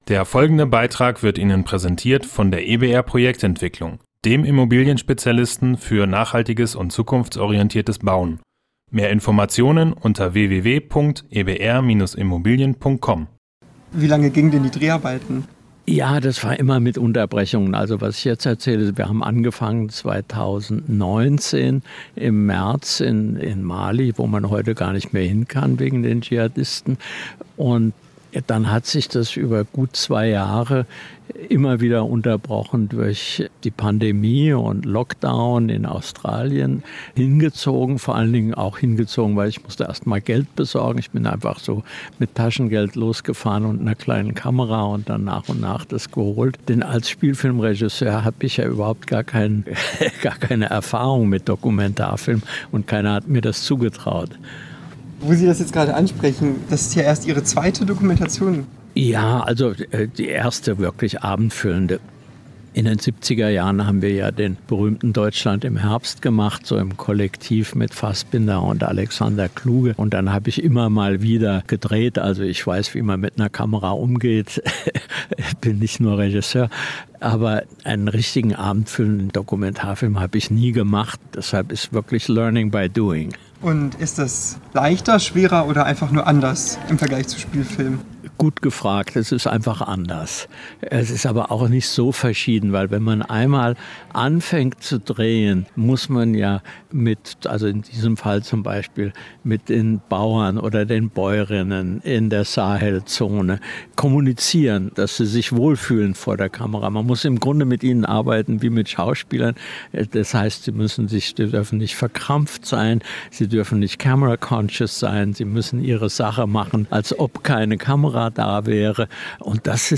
Am vergangenen Mittwoch wurde der Film im Unikino Göttingen vorgeführt. Als besonderer Gast kam Volker Schlöndorff für eine Gesprächsrunde persönlich dazu.